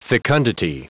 Prononciation du mot fecundity en anglais (fichier audio)
Prononciation du mot : fecundity
fecundity.wav